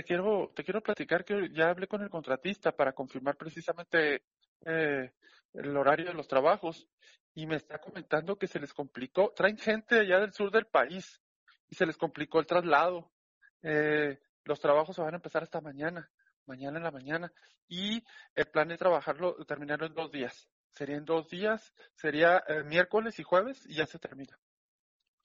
Rodolfo Armendáriz Ronquillo, director de Obra Públicas, detalló un poco más acerca de la situación.